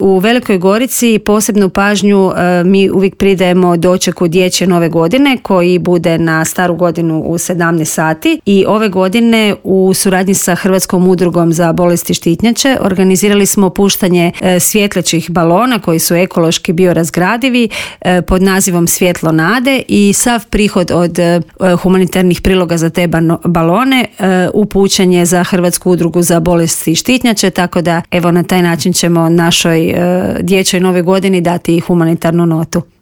Advent u Gorici najavila je u Intervjuu Media servisa